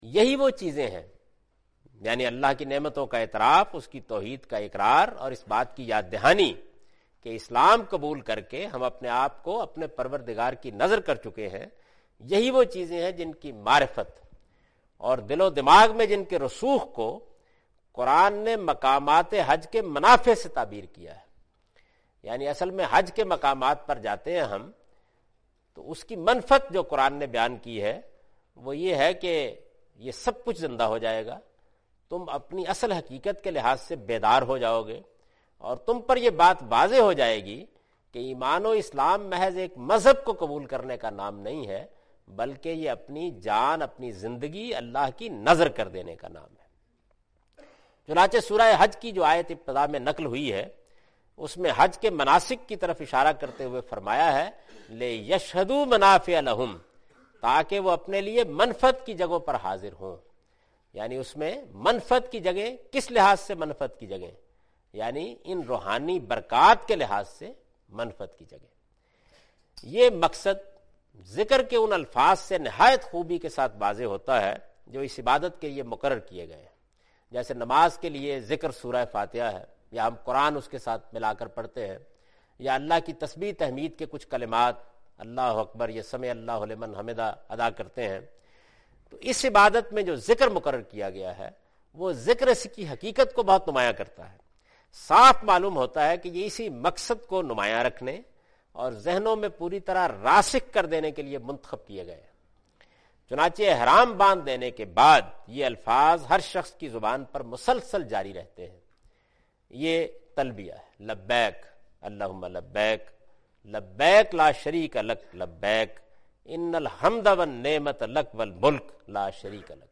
In this video of Hajj and Umrah, Javed Ahmed Ghamdi is talking about "Benefits of Rituals of Hajj".